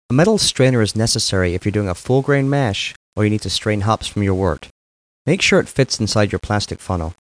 1 channel
strainer.mp3